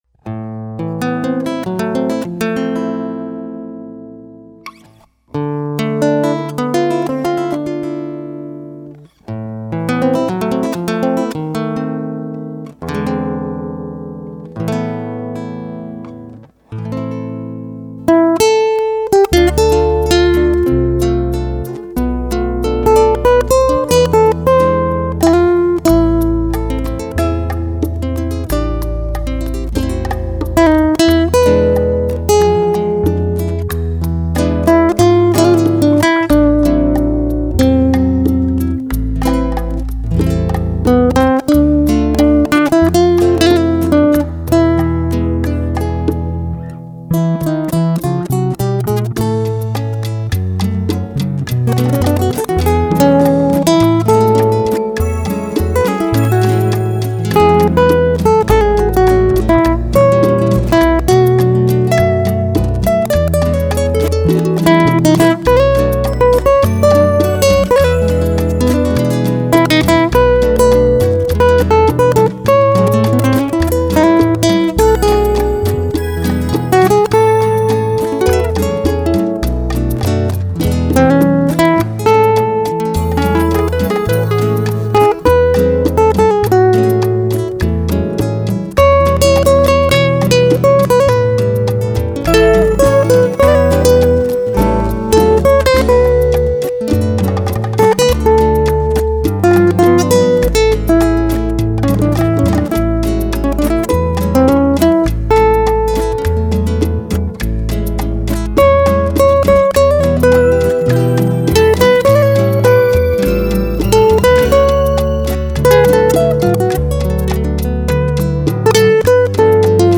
Música popular: instrumental y jazz